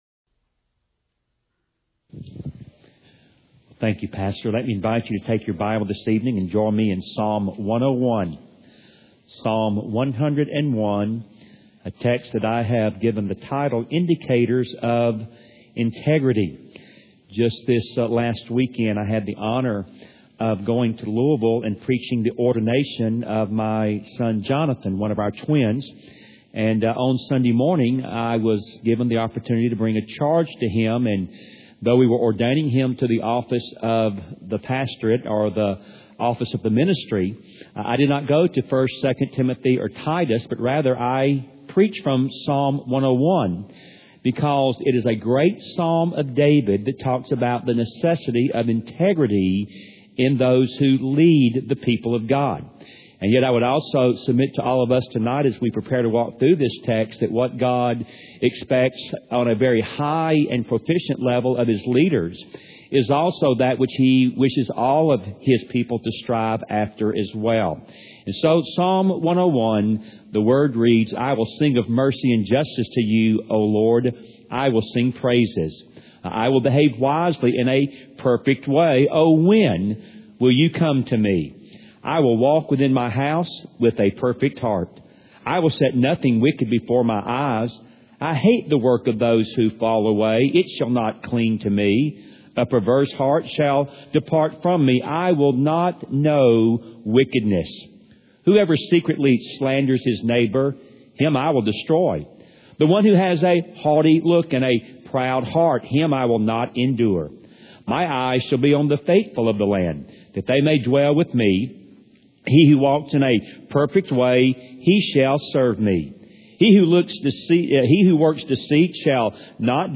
Part of a series entitled “Through the Psalms” delivered at Wake Cross Roads Baptist Church in Raleigh, NC